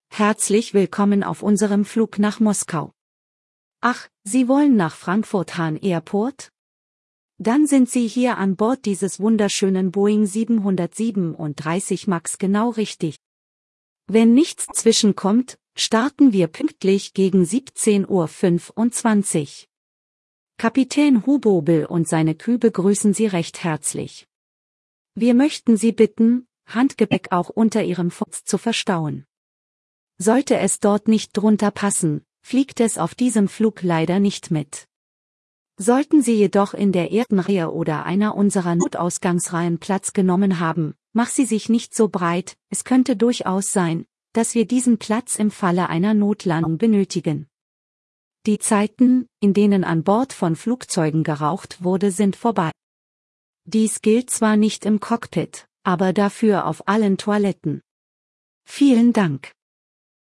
BoardingWelcome.ogg